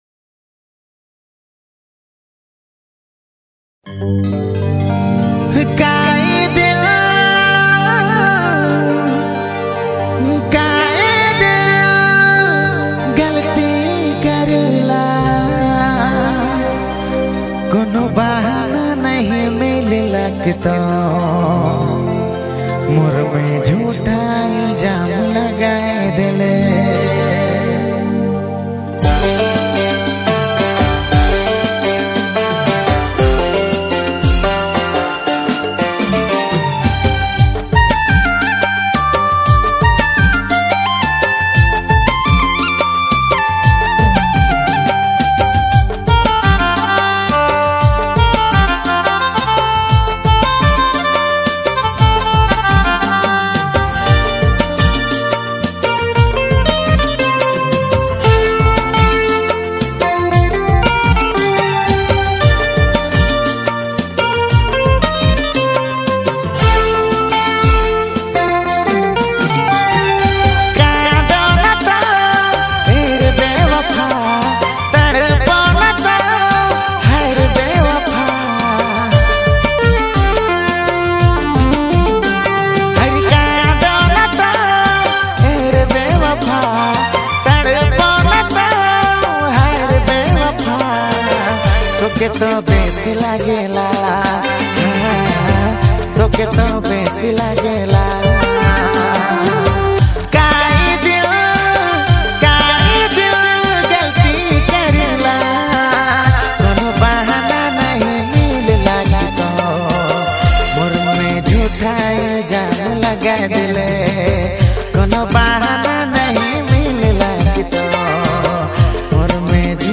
New Nagpuri Dj Songs Mp3 2025